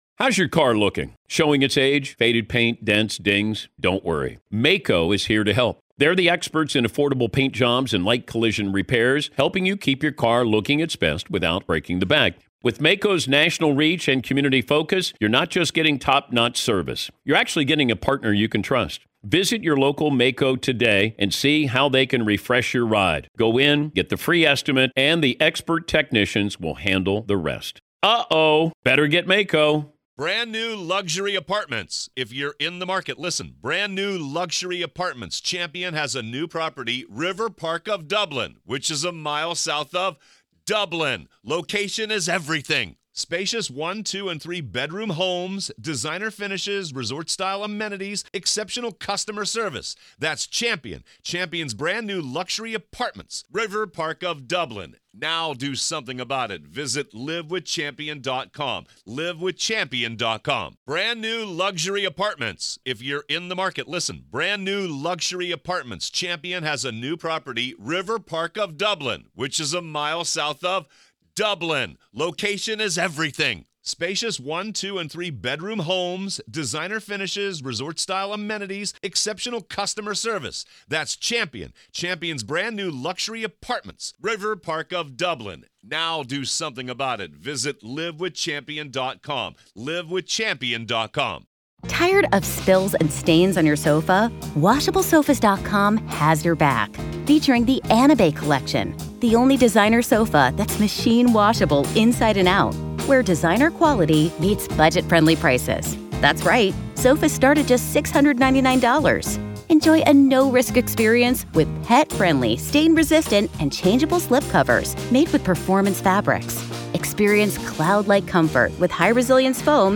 Retired FBI Special Agent